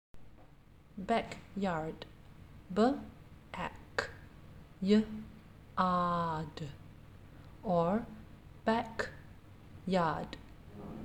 5. Backyard – ‘b’ ‘ack’ ‘y’ ‘ar’ d’ or ‘back’ ‘yard’ (